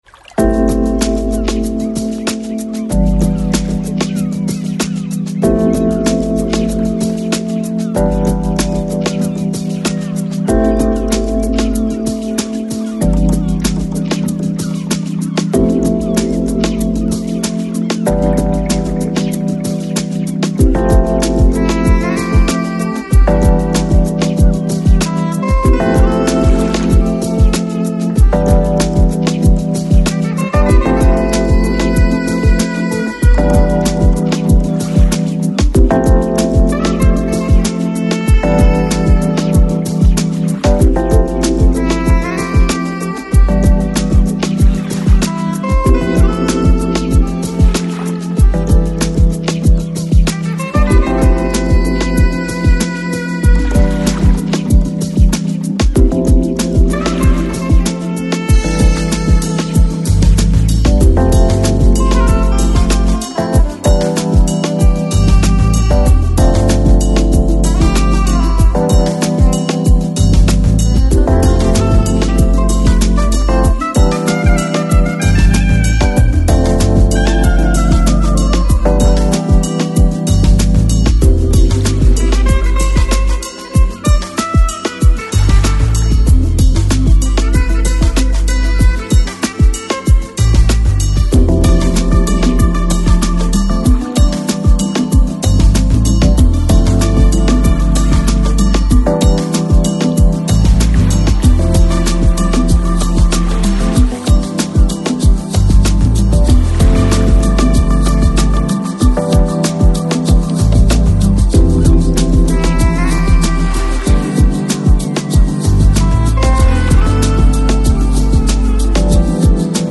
Lounge, Chill Out, Downtempo Год издания